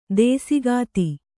♪ dēsigāti